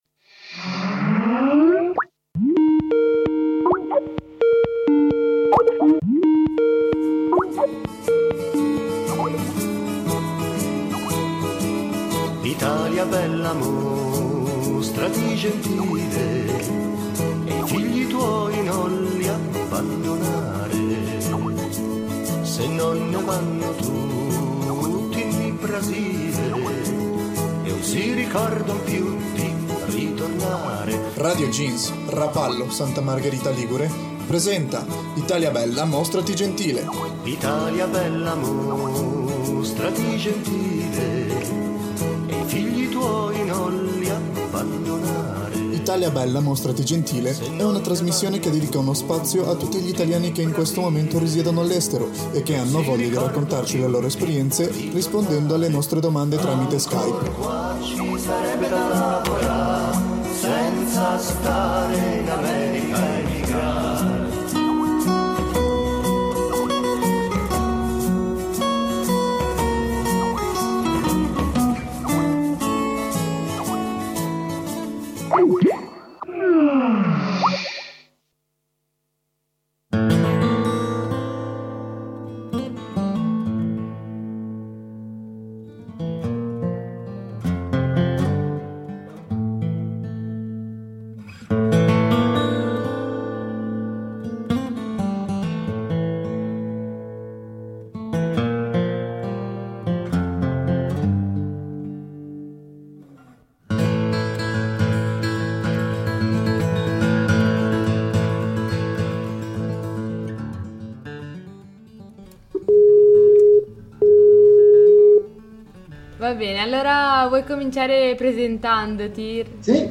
play_circle_filled Italia bella mostrati gentile (puntata 8 - Dublino) Radioweb C.A.G. di Rapallo Nell'ottava puntata del nostro format ci spostiamo a Dublino. Incontriamo via Skype